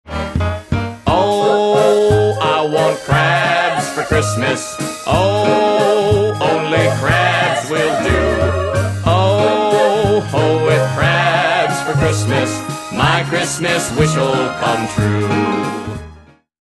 The catchy chorus, the genuine Bawlamerese accent
This hilarious collection of novelty songs includes